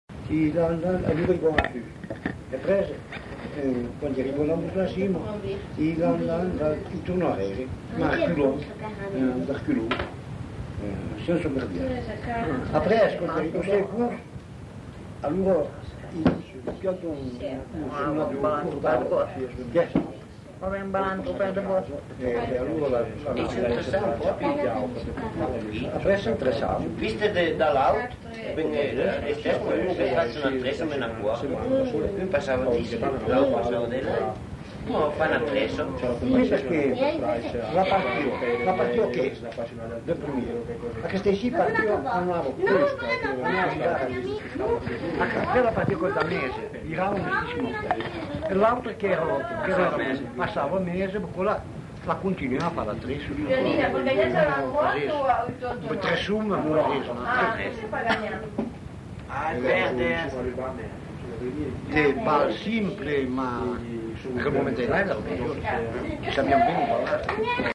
Lieu : Bellino
Genre : témoignage thématique